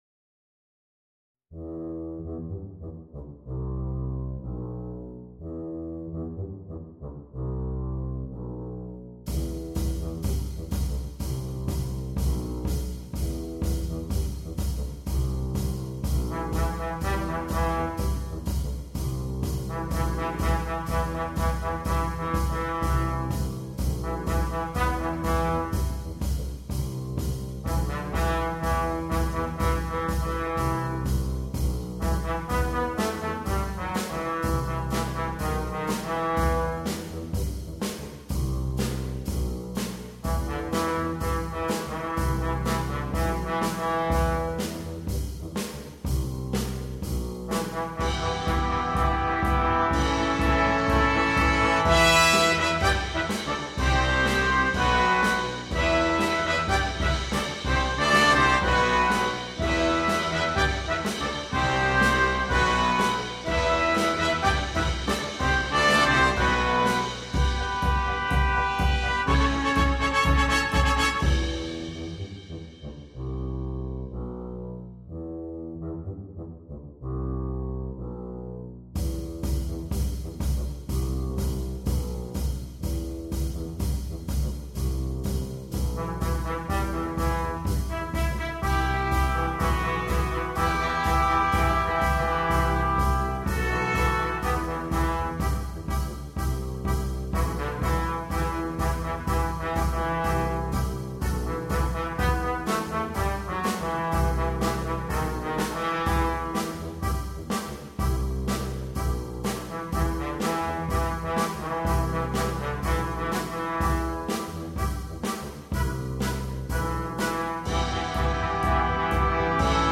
для брасс-квинтета